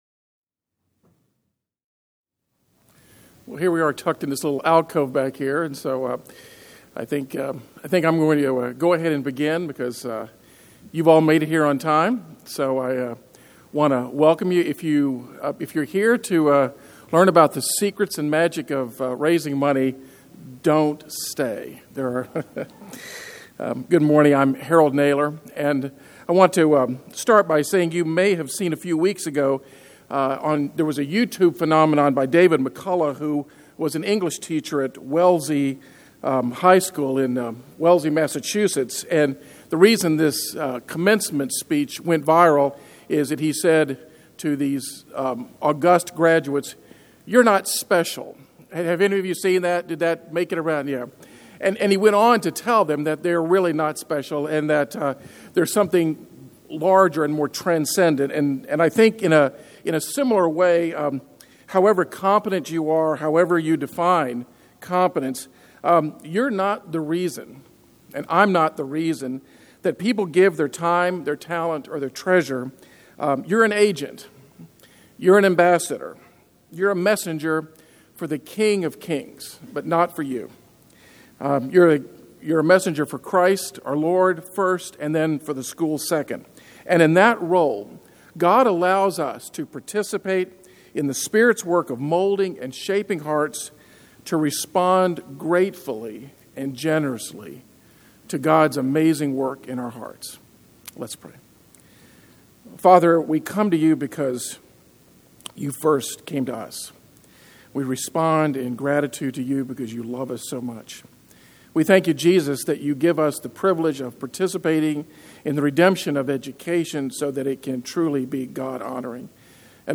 2012 Workshop Talk | 0:51:48 | Fundraising & Development